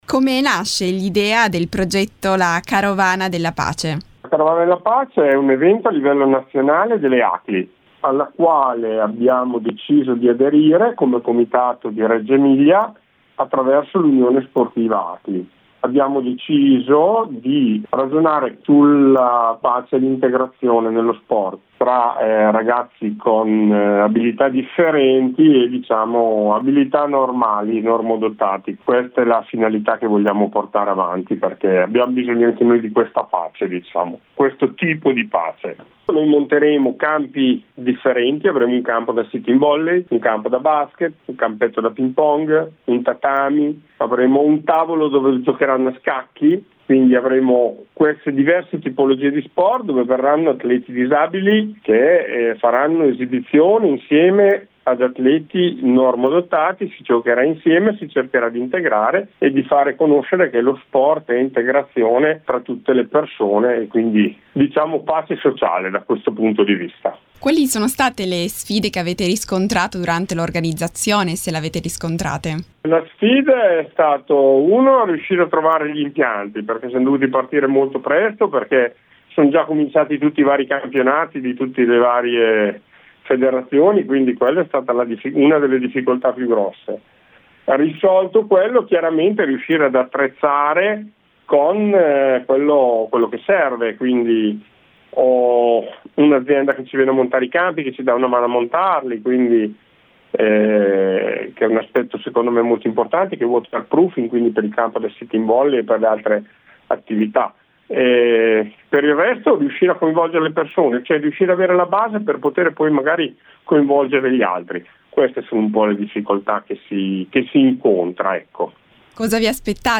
Home Magazine Interviste Reggio Emilia ospita la Carovana della Pace “Peace at Work – L’Italia...